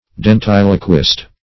Search Result for " dentiloquist" : The Collaborative International Dictionary of English v.0.48: Dentiloquist \Den*til"o*quist\, n. One who speaks through the teeth, that is, with the teeth closed.
dentiloquist.mp3